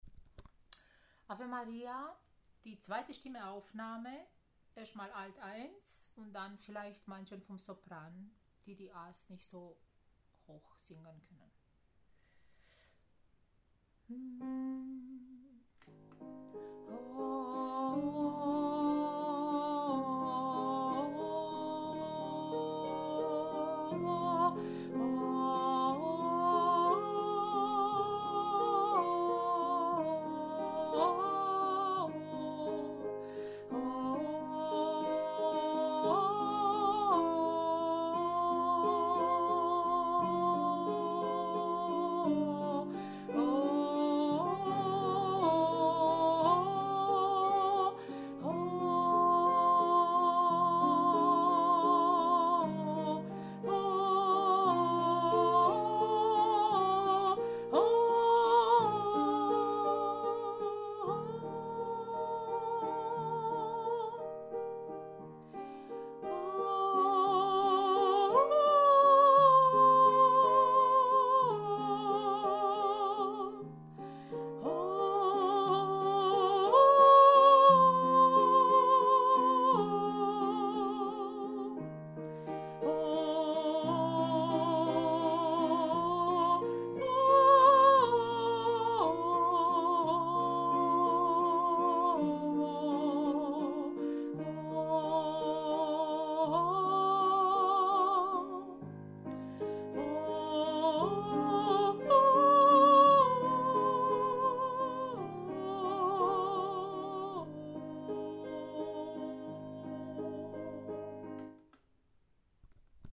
Ave Maria – Alto 1/Sopran 2 -TeilB